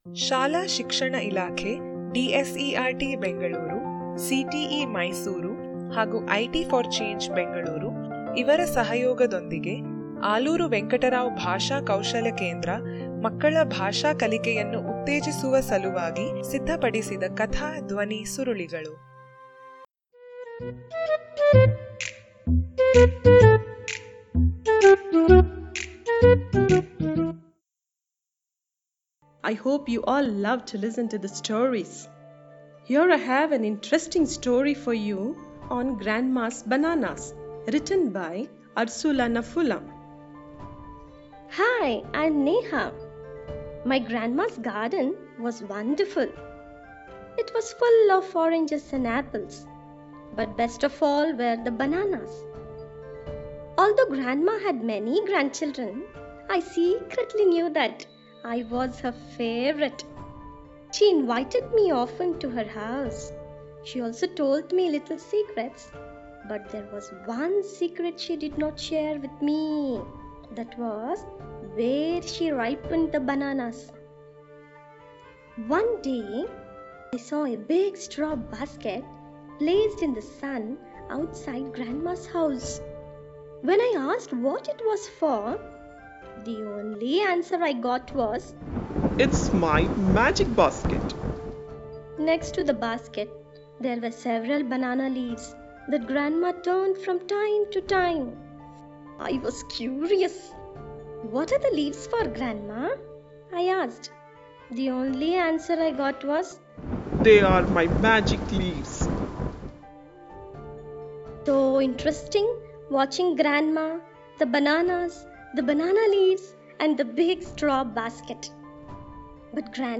Grandma’s Bananas - Audio Story Activity Page